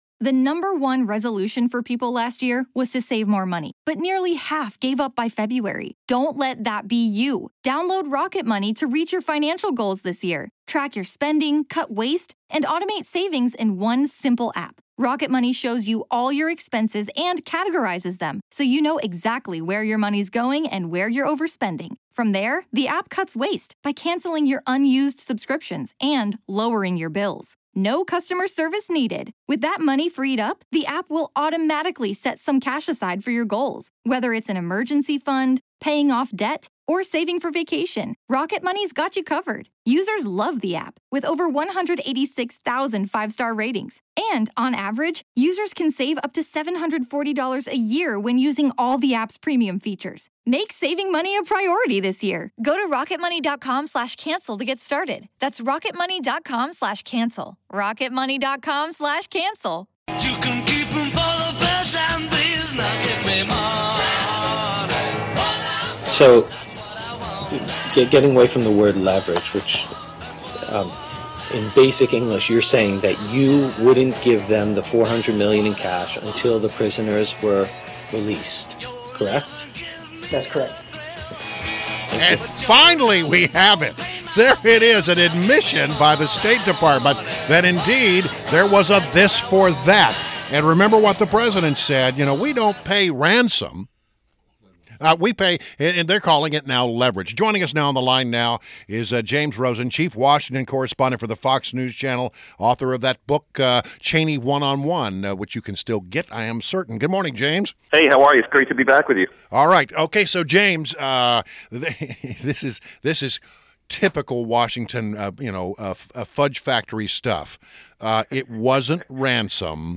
WMAL Interview - JAMES ROSEN -08.19.16